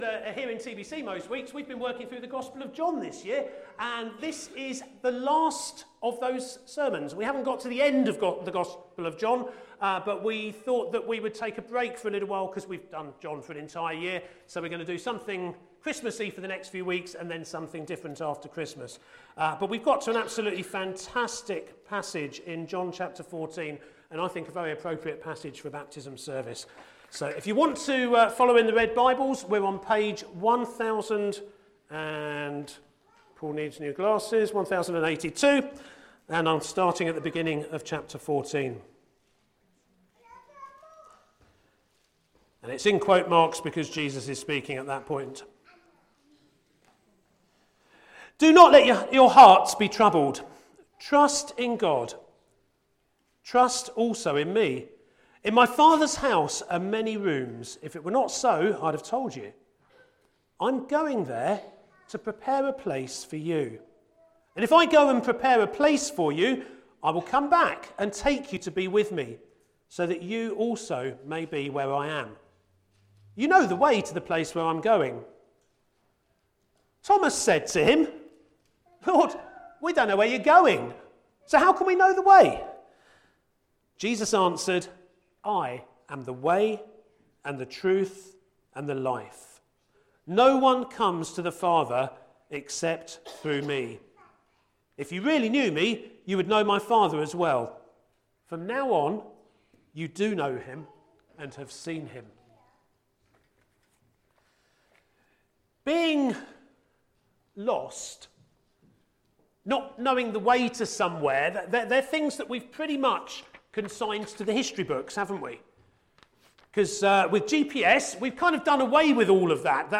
A message from the series "God: in Person."